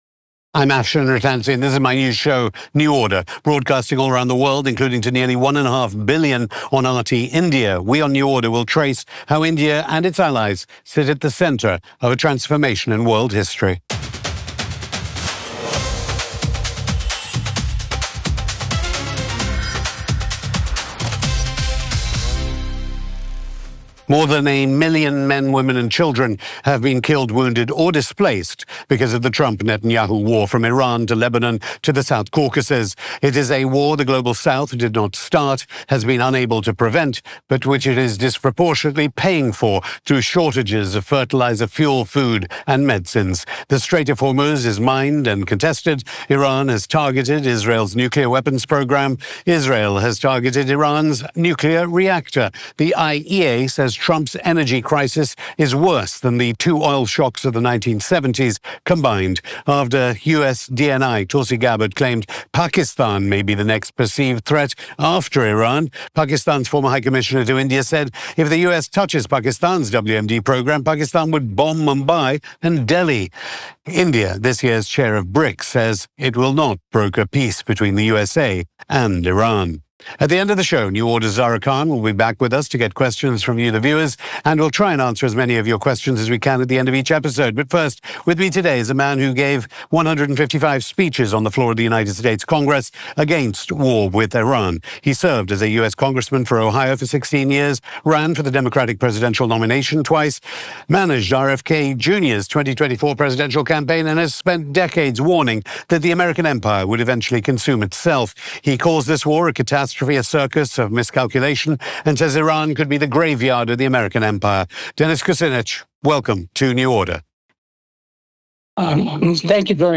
On this episode of New Order, we speak to Dennis Kucinich, former US Congressman for Ohio, and Former Mayor of Cleveland, Ohio.